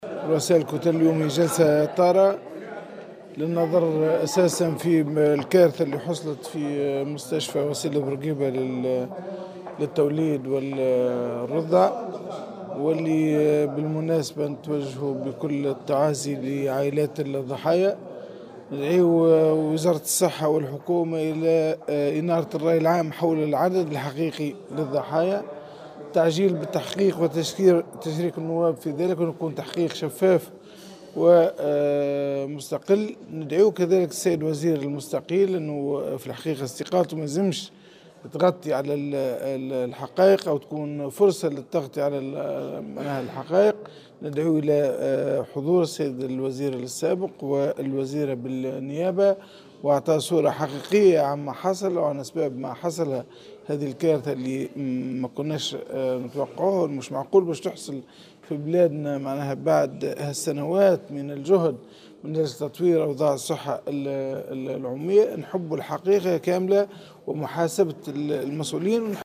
وأضاف في تصريح اليوم لمراسل "الجوهرة أف أم" أنه سيتم أيضا خلال هذه الجلسة مطالبة الحكومة بكشف الحقيقة كاملة ومحاسبة المتورطين.